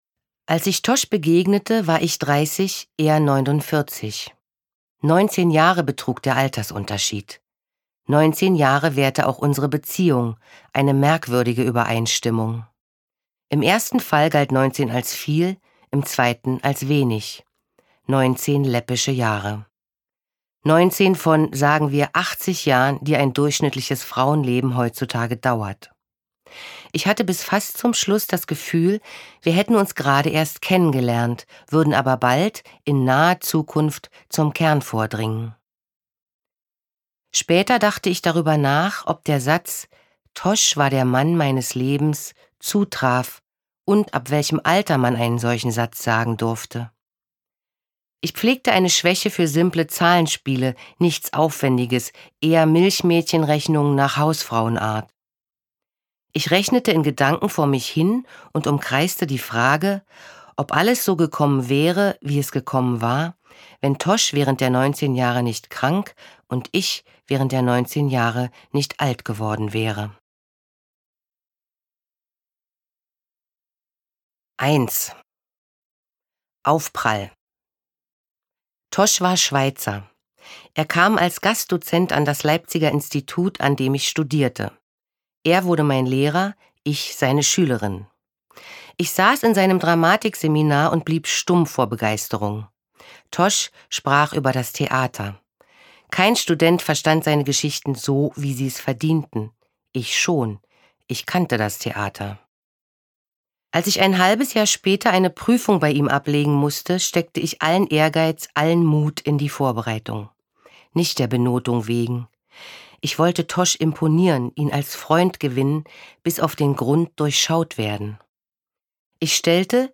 Sprecher Katja Oskamp